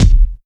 Traumah Oz Kick.wav